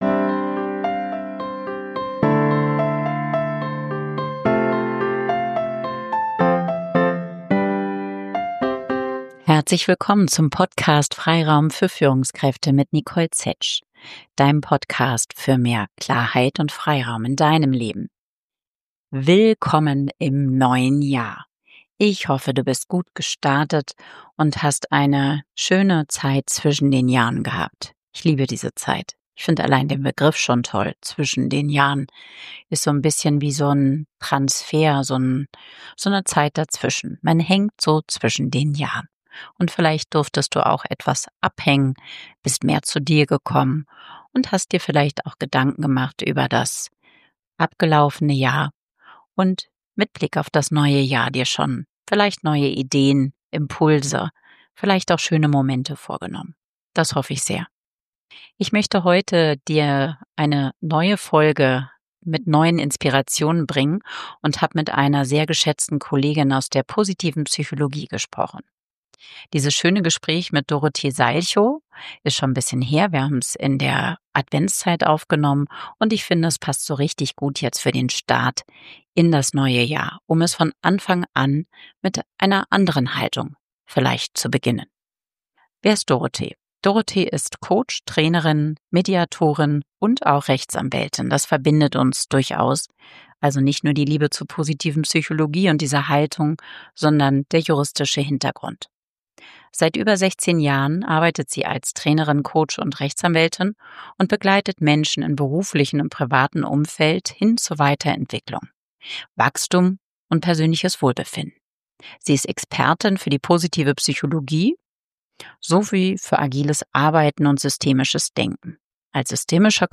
#83 Haltung, die trägt - ein Gespräch